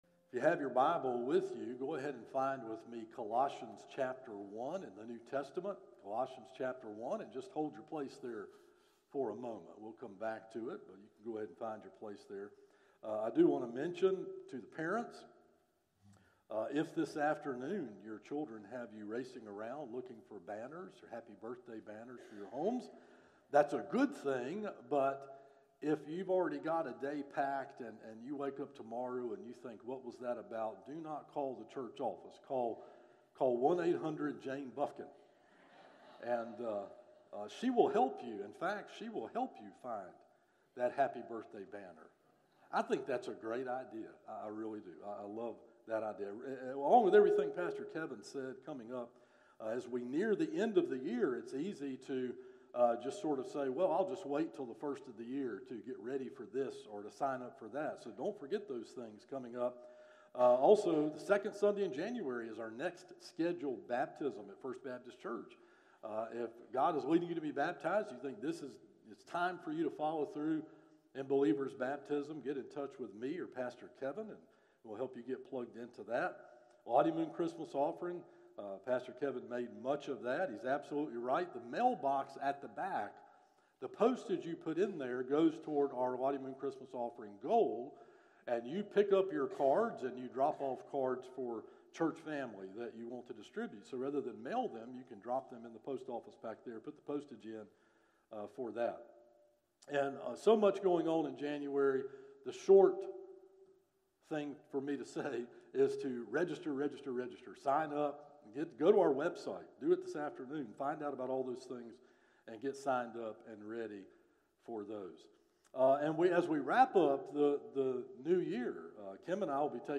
Sermons - First Baptist Church of Shallotte
From Series: "Morning Worship - 11am"